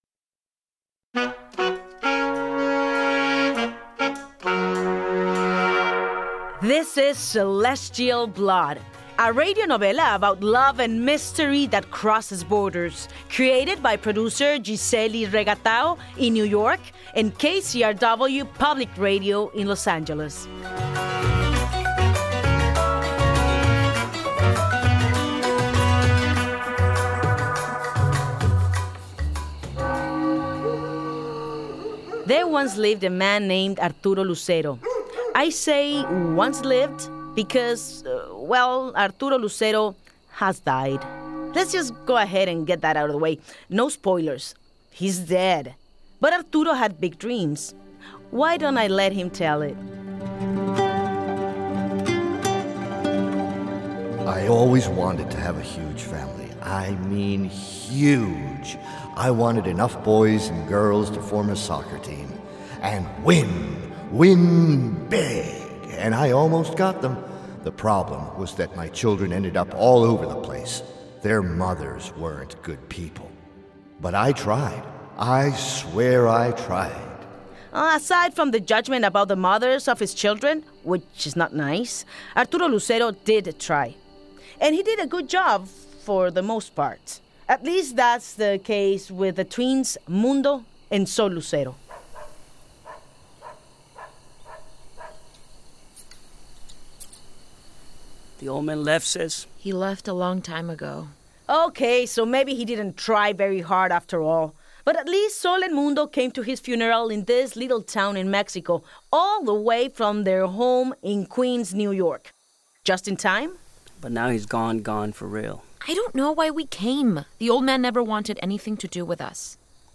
Kate del Castillo: Narrator